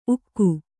♪ ukku